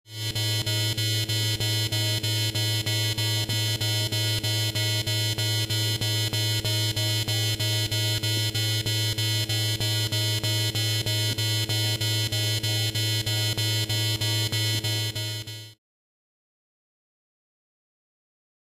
Alarm Klaxon | Sneak On The Lot
Large Synth Siren Pulsing, Steady.